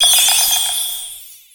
Cri d'Hexagel dans Pokémon X et Y.